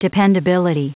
Prononciation du mot dependability en anglais (fichier audio)
Prononciation du mot : dependability
dependability.wav